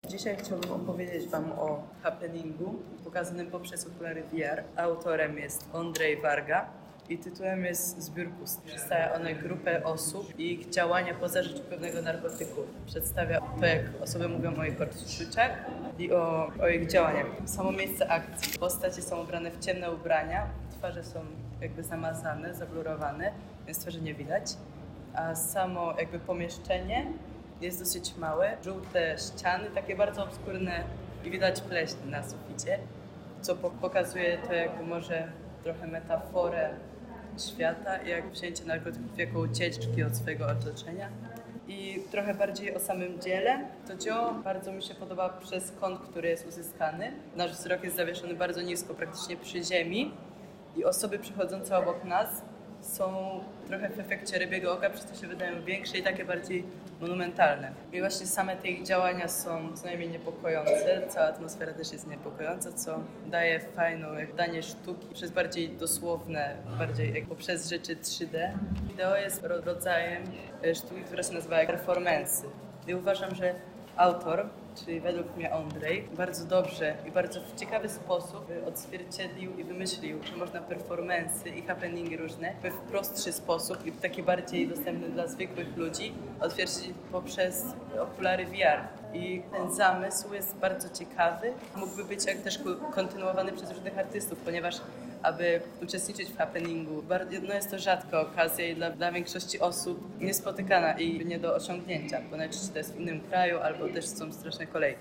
Obraz w ruchu. Interwencja: Audioprzewodnik